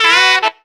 HARM RIFF 6.wav